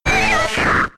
Cri d'Aspicot K.O. dans Pokémon X et Y.